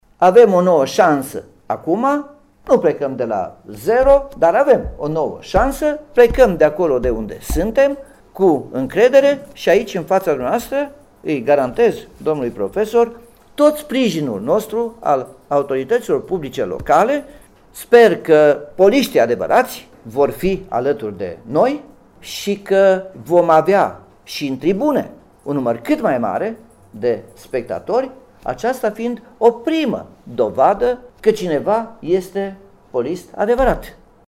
De altfel, o bună parte a conferinţei de presă pentru prezentarea noului antrenor a fost prilej de regrete faţă de încheierea “mandatului Alexa”.